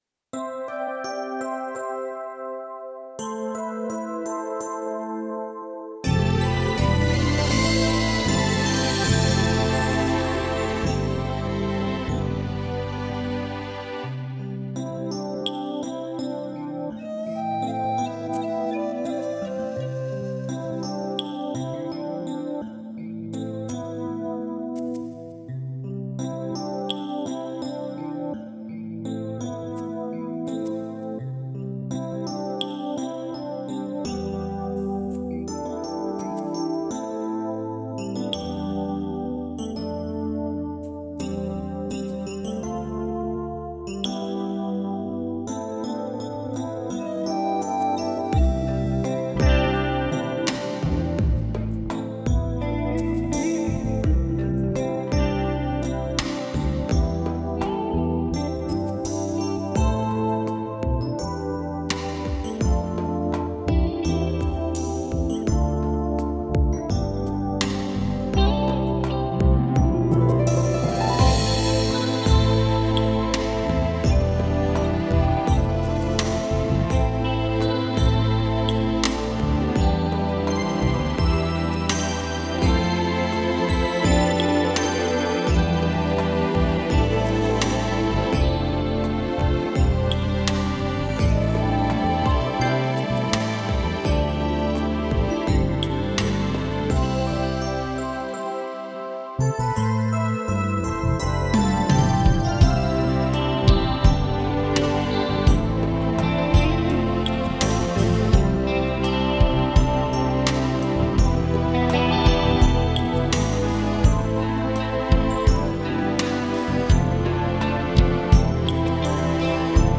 Genre: Soft rock.